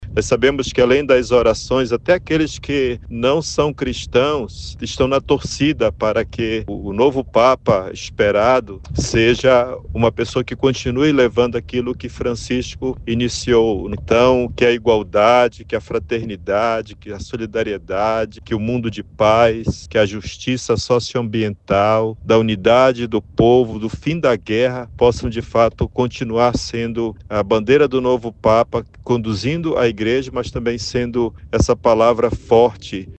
Dom Hudson também ressalta a importância de um novo pontificado voltado para as realidades da Amazônia, o cuidado com a Casa Comum, a justiça social e o acolhimento a todos.